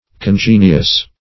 Congenious \Con*gen"ious\, a.